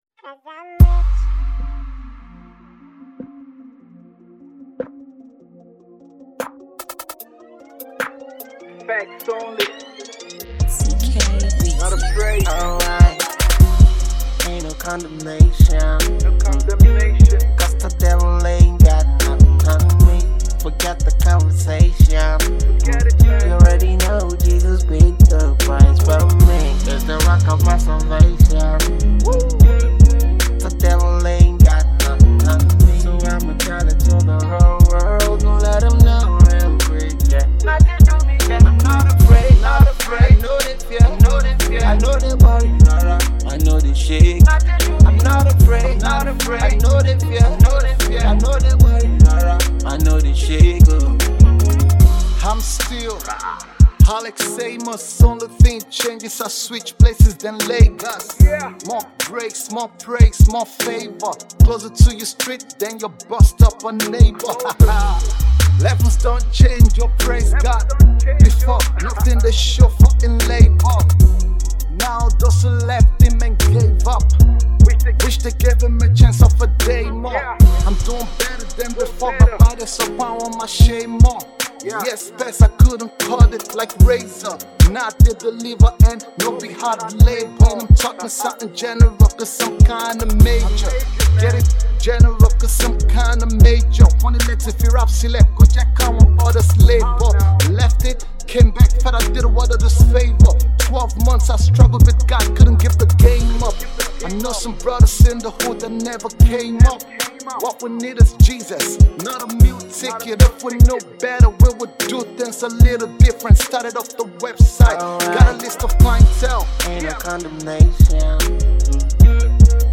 Rap & Hip Hop single
adapts the Trap music style, with a catchy hook